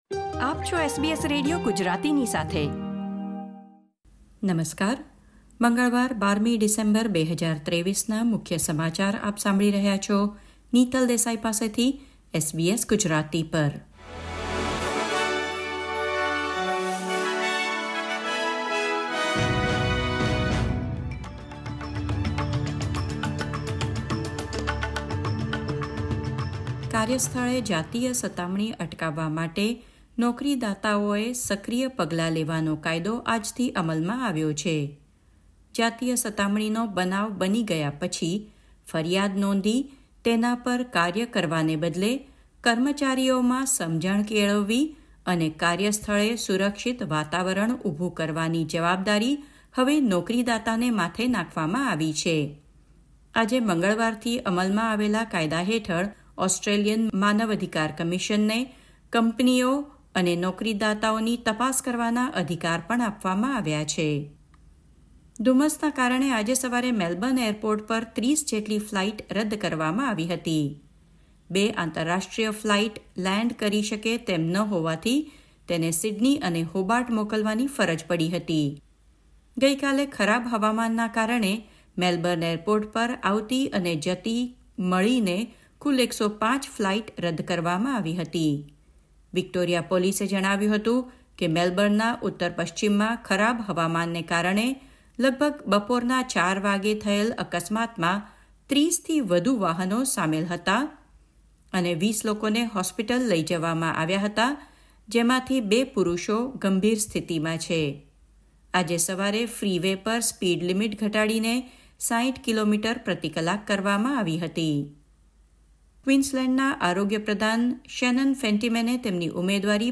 SBS Gujarati News Bulletin 12 December 2023